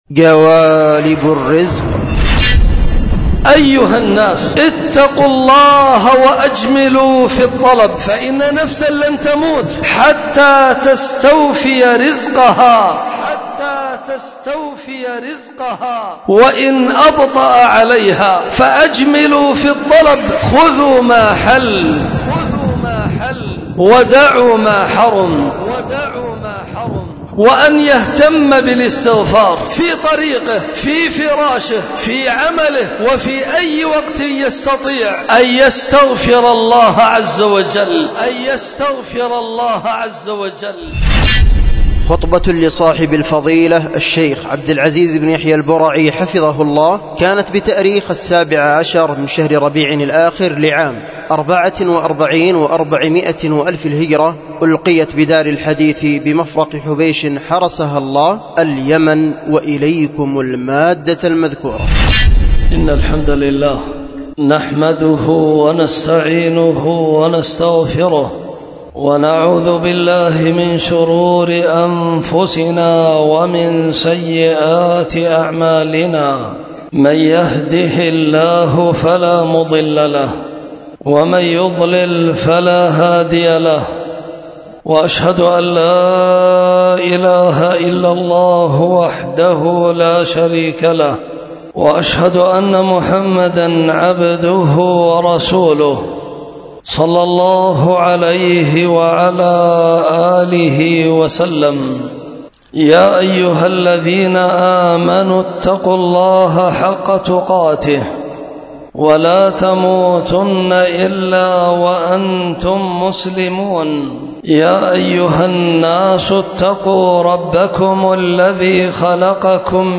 خطبة
ألقيت بدار الحديث بمفرق حبيش